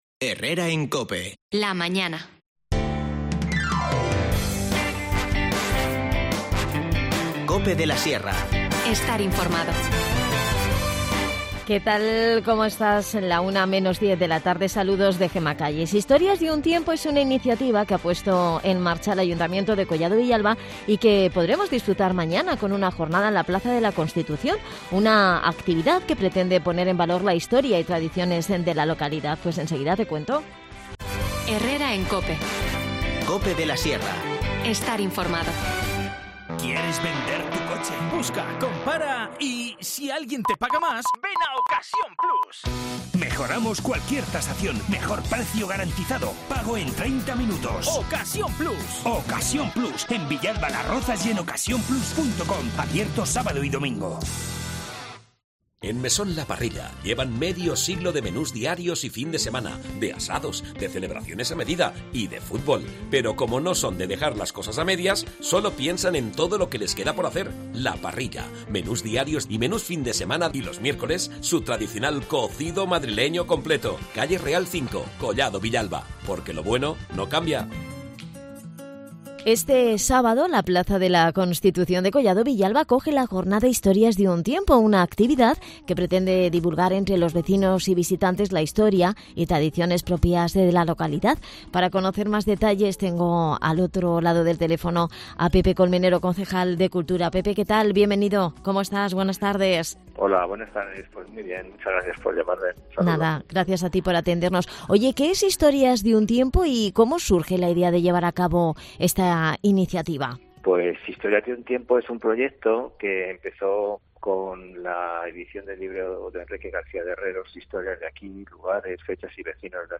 Nos cuenta todos los detalles, Pepe Colmenero, concejal de Cultura Toda la actualidad en Cope de la Sierra Escucha ya las desconexiones locales de COPE de la Sierra e n Herrera en COPE de la Sierra y Mediodía COPE de la Sierra . Las desconexiones locales son espacios de 10 minutos de duración que se emiten en COPE, de lunes a viernes.